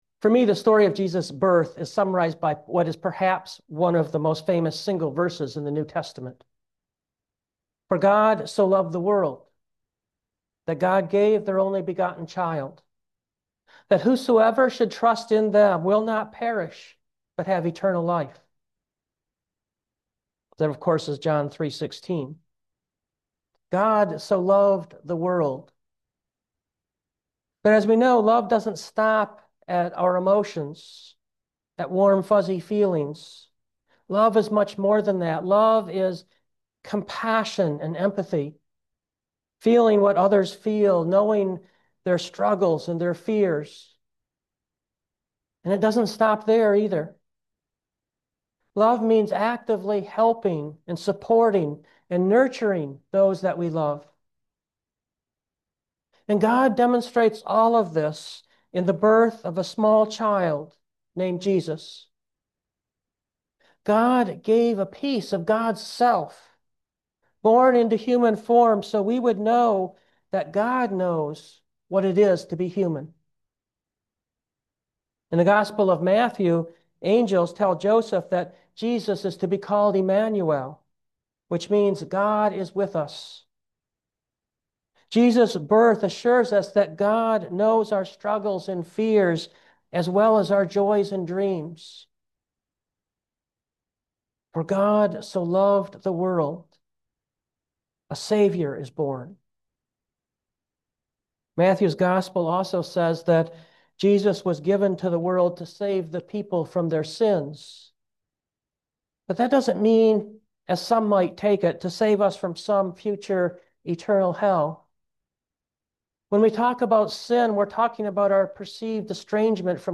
In addition to Romans, we also heard a paraphrased version of Jesus’ birth story: